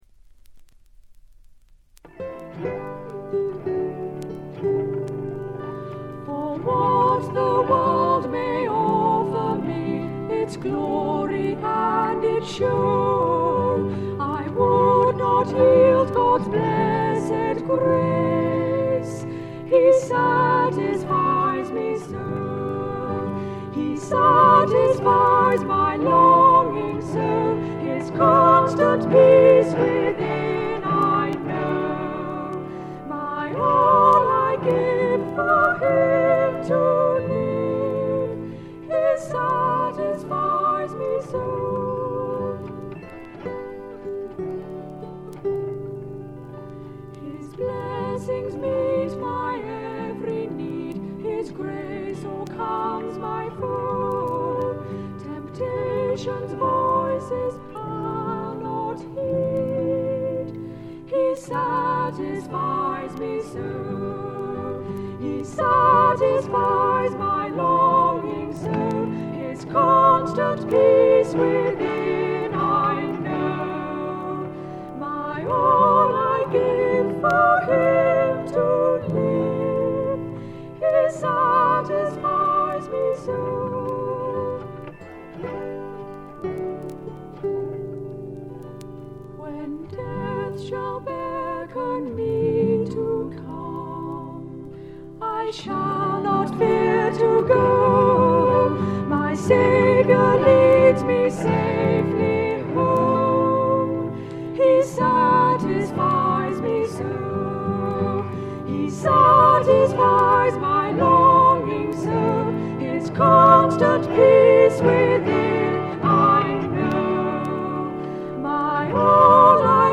細かなバックグラウンドノイズ、チリプチは出ていますが鑑賞を妨げるようなノイズはありません。
本人たちのオートハープとギターのみをバックに歌われる美しい歌の数々。純粋で清澄な歌声にやられてしまいますよ。
試聴曲は現品からの取り込み音源です。